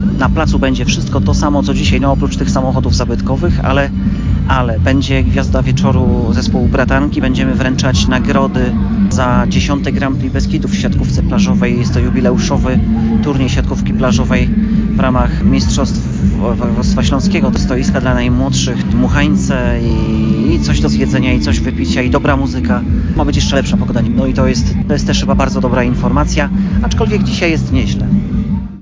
Dwudniowe święto gminy, również dzisiaj zakończy dyskoteka z Radiem Bielsko, jednak nie będzie już można zobaczyć klasyków, mówi wójt Milówki Robert Piętka.